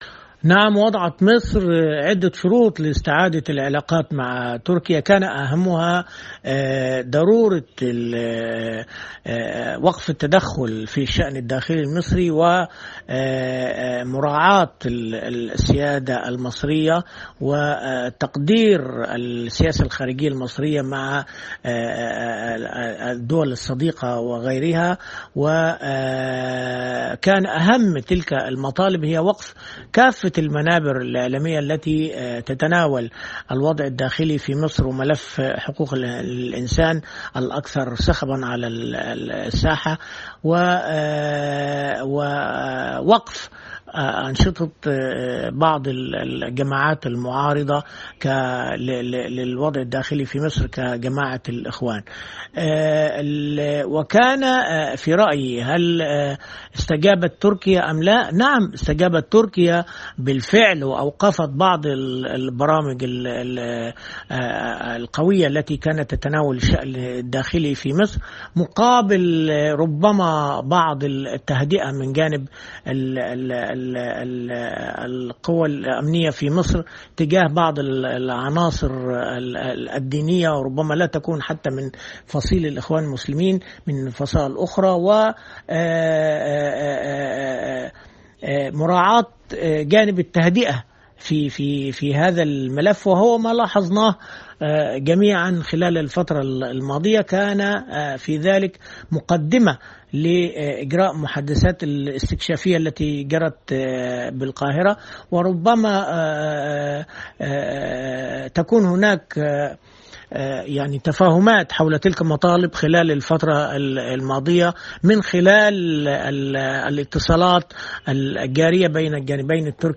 حوار